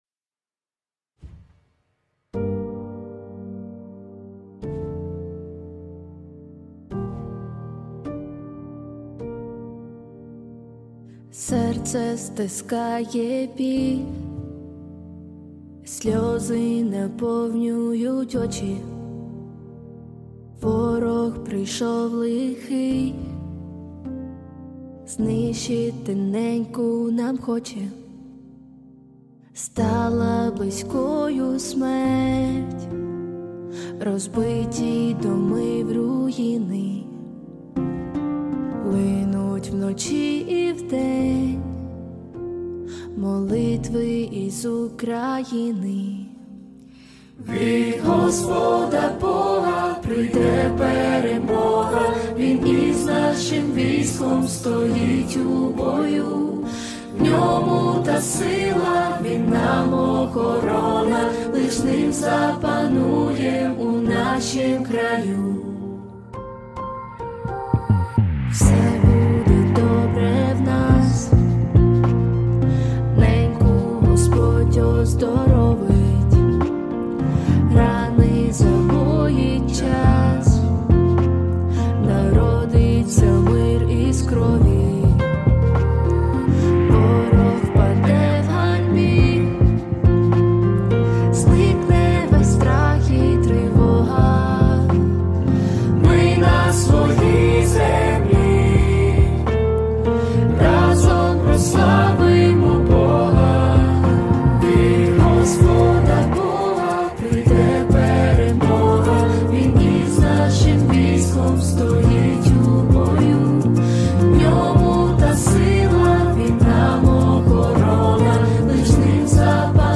1852 просмотра 582 прослушивания 138 скачиваний BPM: 105